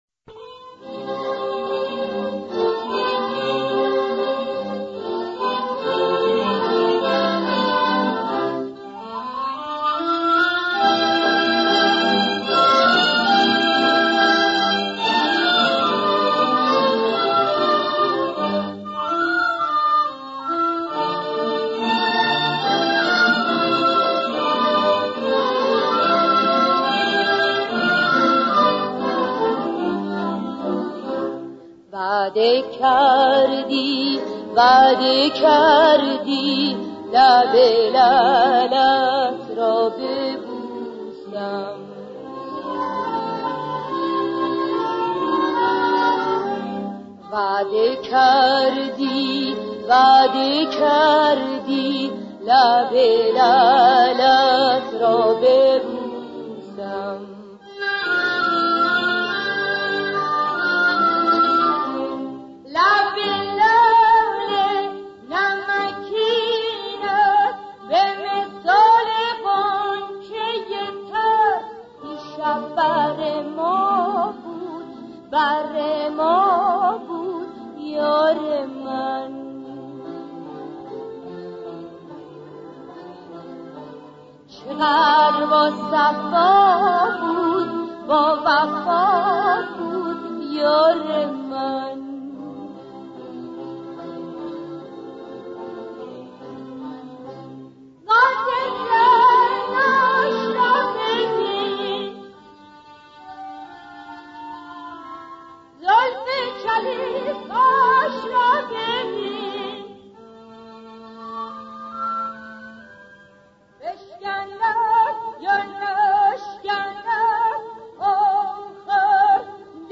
در مقام ماهور